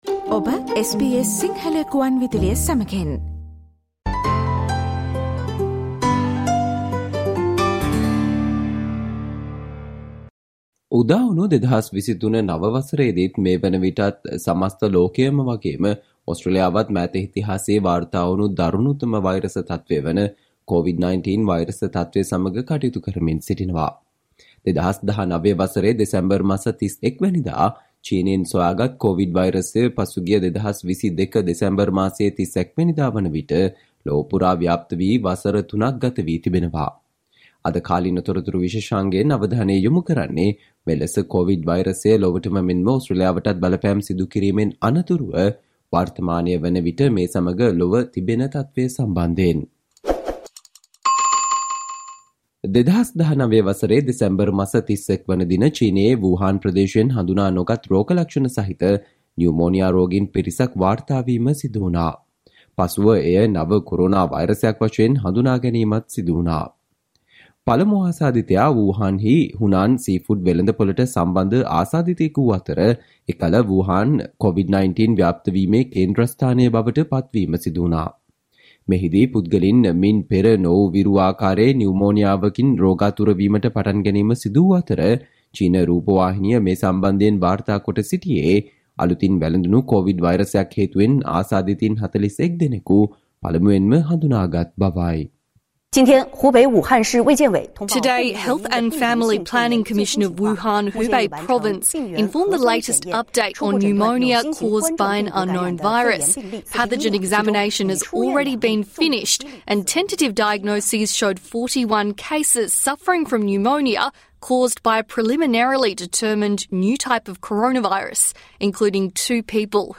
ජනවාරි 02 වන දා සඳුදා ප්‍රචාරය වූ SBS සිංහල සේවයේ කාලීන තොරතුරු විශේෂාංගයට සවන්දෙන්න.